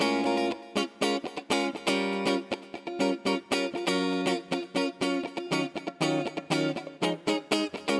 17 Rhythm Guitar PT1.wav